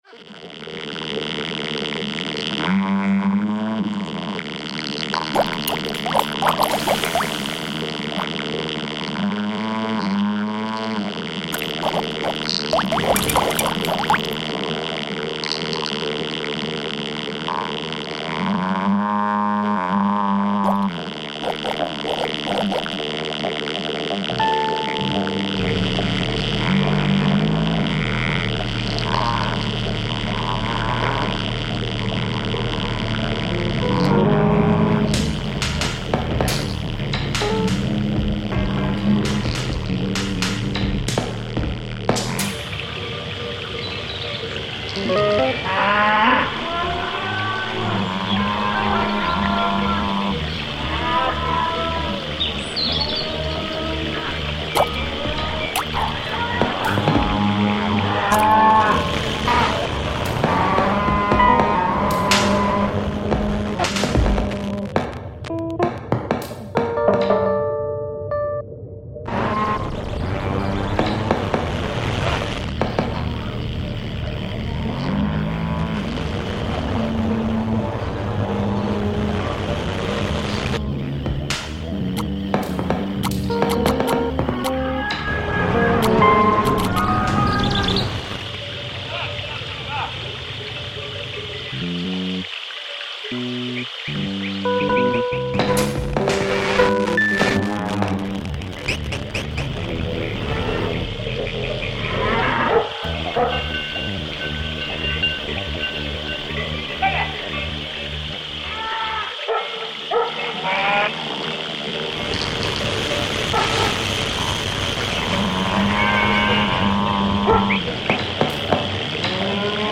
Vilarinha, Portugal reimagined